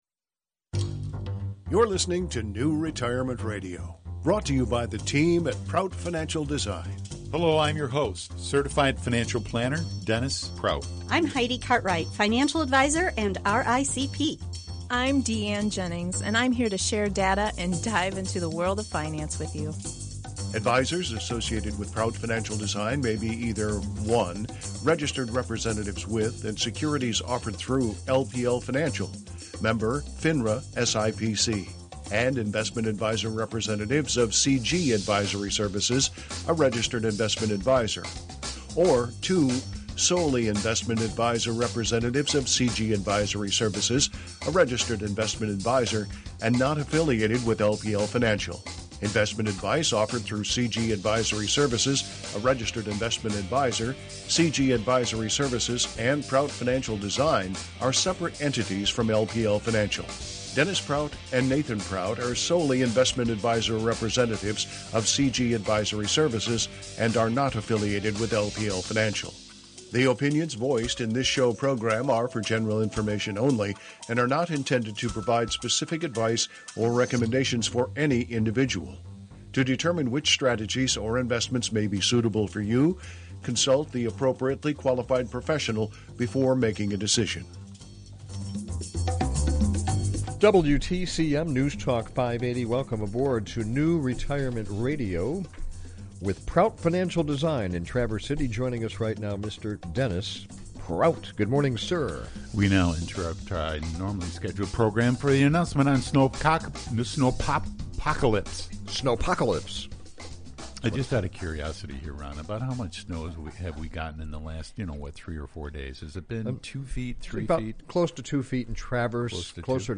We’re Back LIVE!
To make up for our absence, we would like to dedicate a portion of today’s radio show to answer your calls/texts regarding year-end concerns, including IRA contributions, Roth conversions and RMDs.